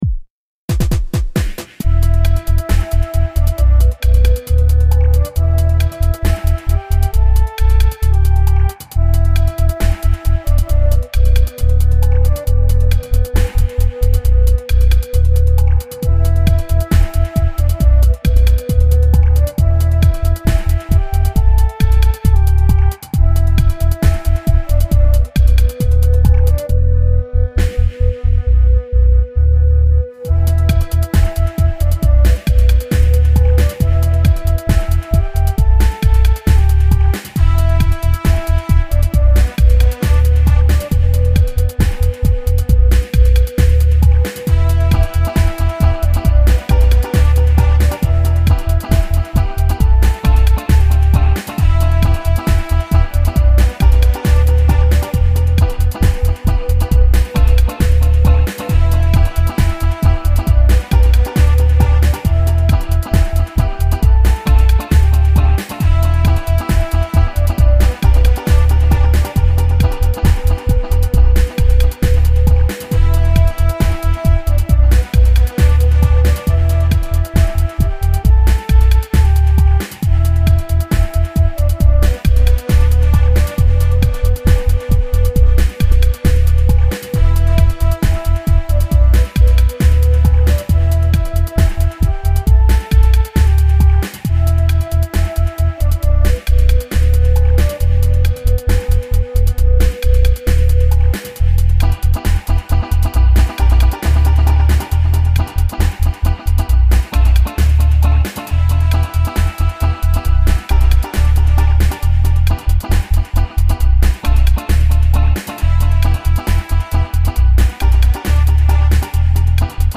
Steppa dub tune made with Reason and Consciousness.